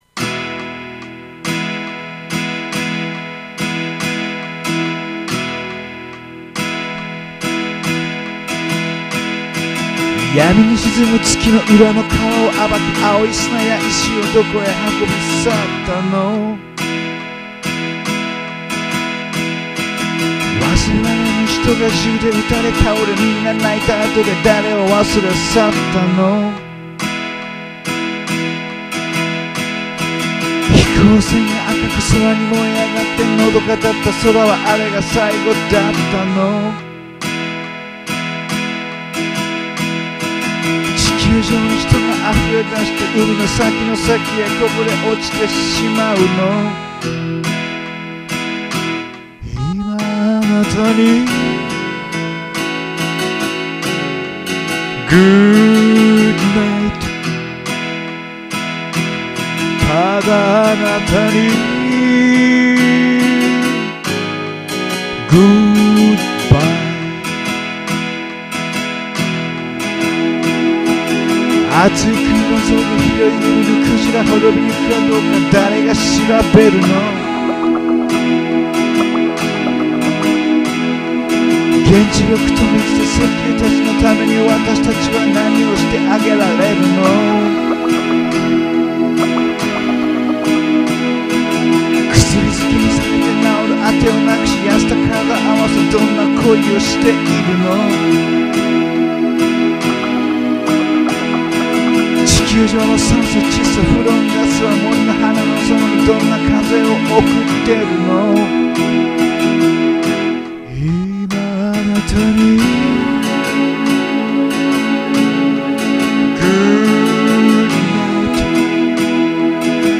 JOU SOUND でホームカラオケしたものを録音いたしまして、
書き下ろしたバラード。